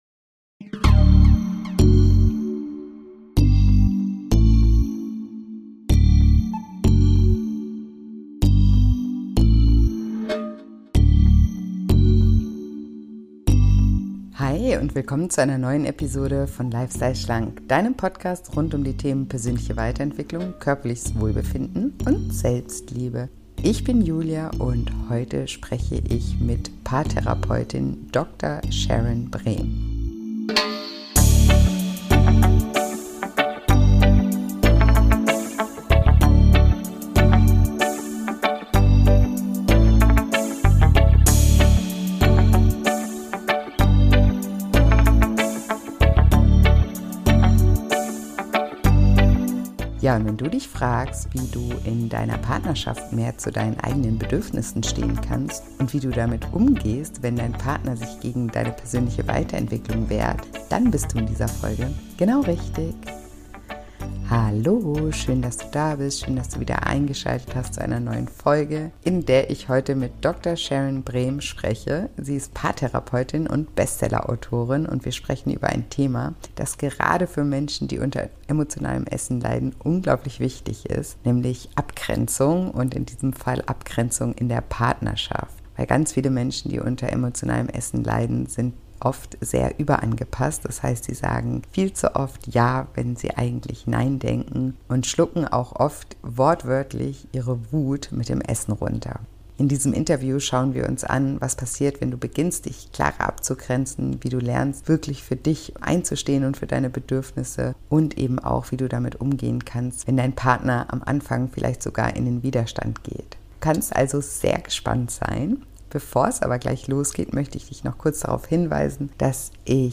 Ein wirklich spannendes Interview, das du auf keinen Fall verpassen solltest.